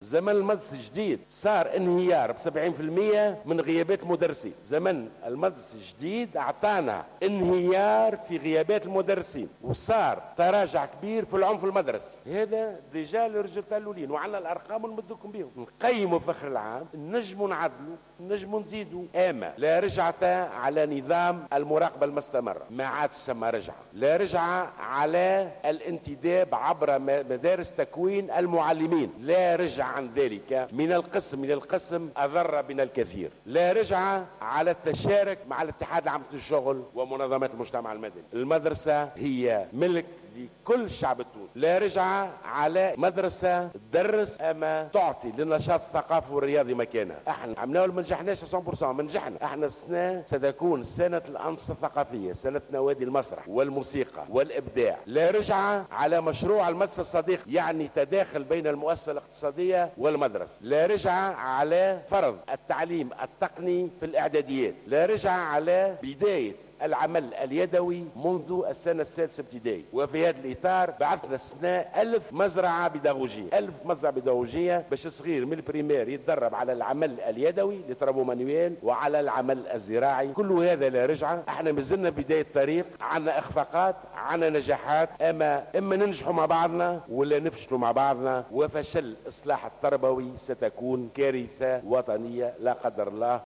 أكد وزير التربية، ناجي جلول خلال الجلسة العامة بمجلس نواب الشعب المنعقدة اليوم الجمعة لمناقشة ميزانية وزارته، أنه لا رجعة عن نظام المراقبة المستمرة وعن الزمن المدرسي المعتمد حاليا، مضيفا أنه ستكون هنالك إجراءات أخرى قابلة للتعديل والنقاش مع مختلف الأطراف.